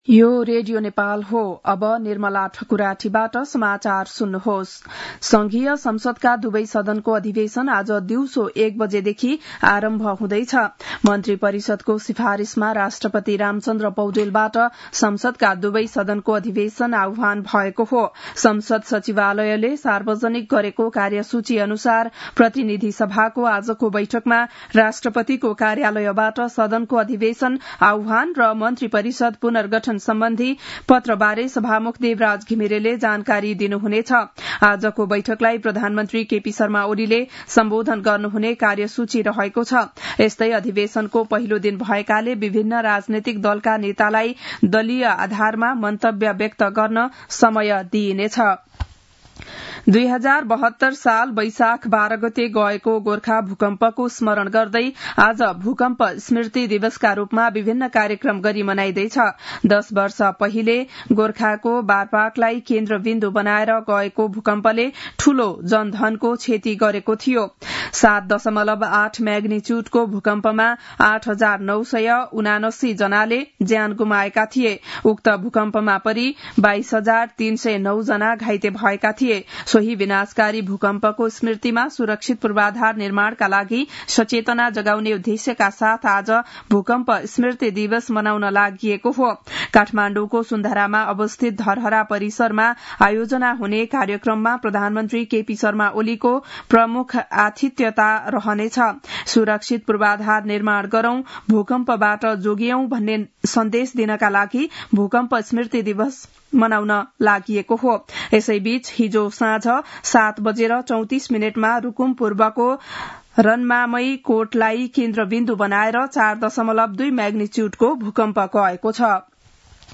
बिहान ११ बजेको नेपाली समाचार : १२ वैशाख , २०८२